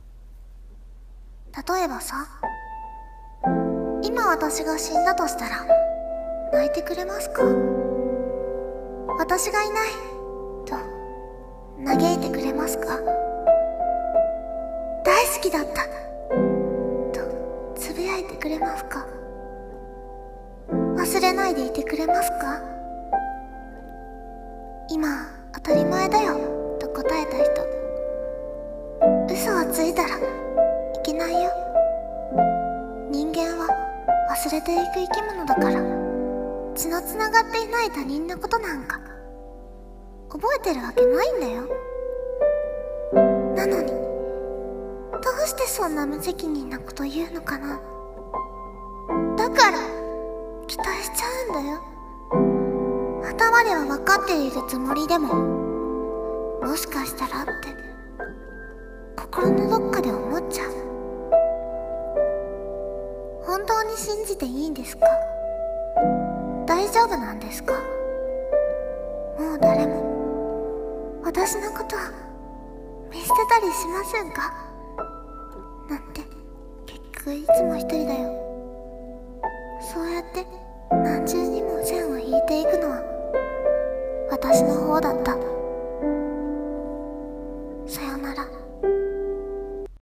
【朗読台本】ここからそっち【一人声劇】